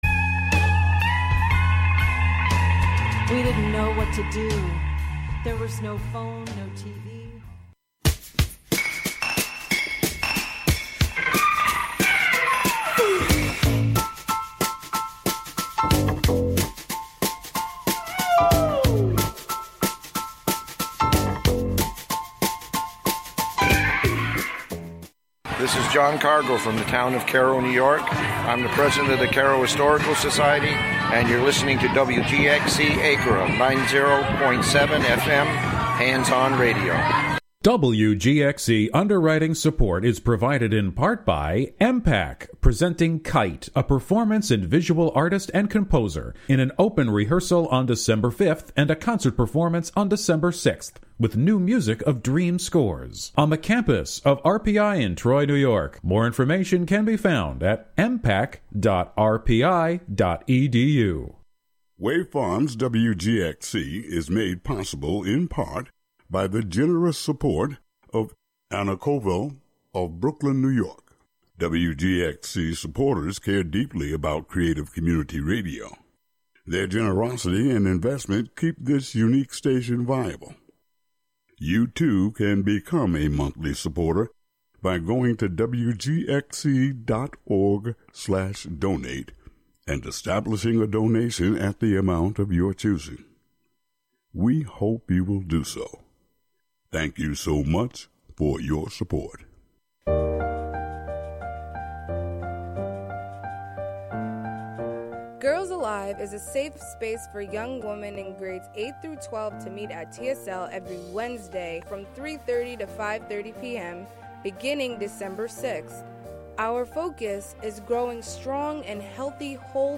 Live from the Catskill Maker Syndicate space on W. Bridge St. in Catskill, "Thingularity" is a monthly show about science, technology, fixing, making, hacking, and breaking with the amorphous collection of brains comprising the "Skill Syndicate."